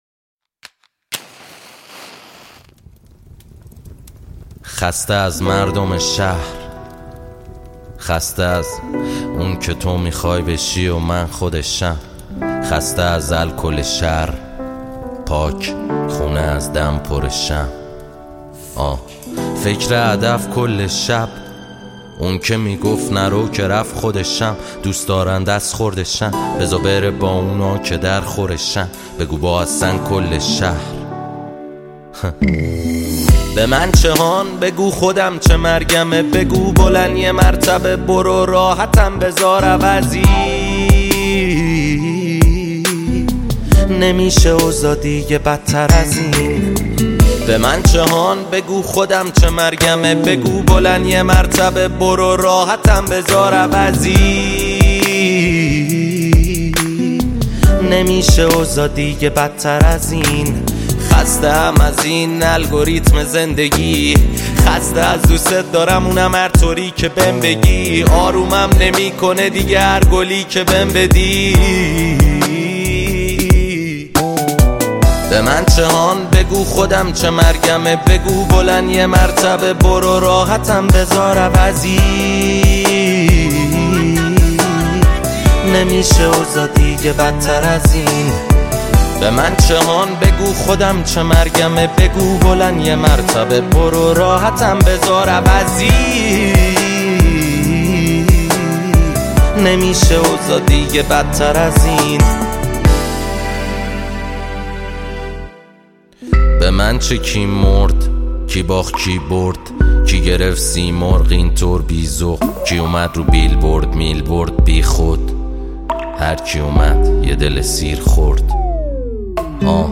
آراَندبی